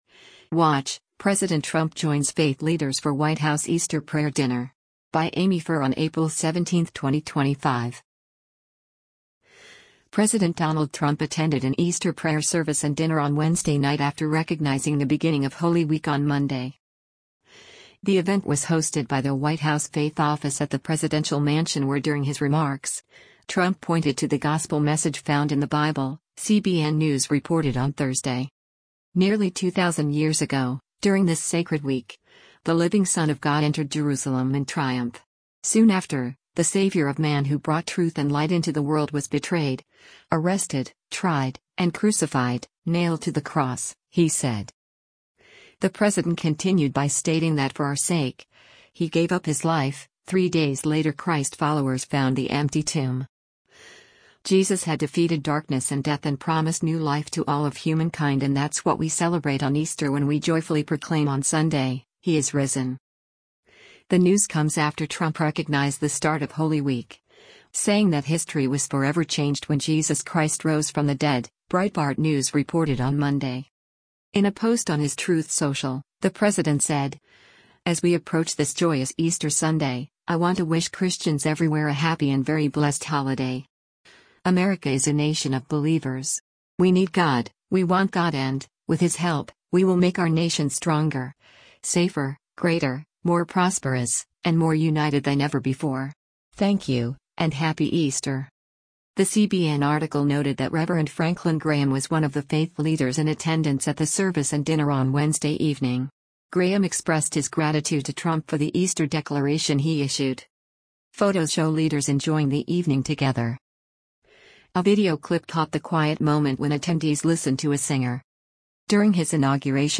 The event was hosted by the White House Faith Office at the presidential mansion where during his remarks, Trump pointed to the gospel message found in the Bible, CBN News reported on Thursday.